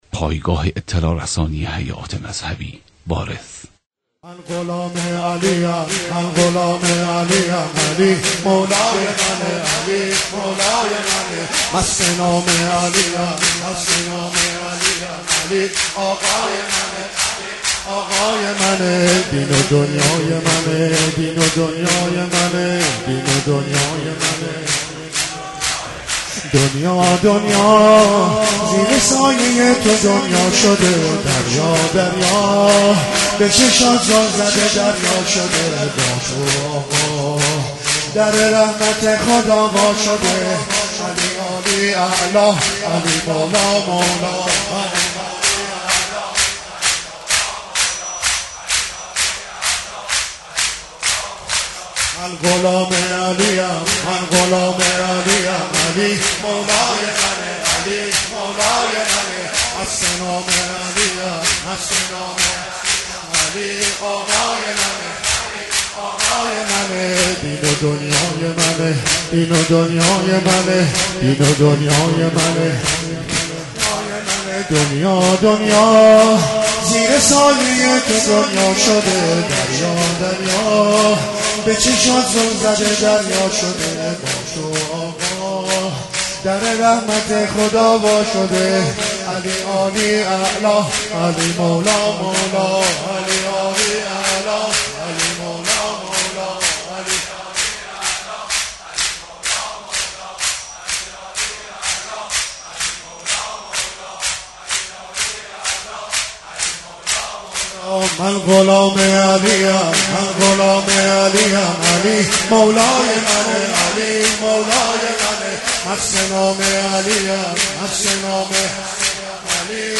مداحی حاج عبدالرضا هلالی به مناسبت شهادت امیرالمومنین (ع)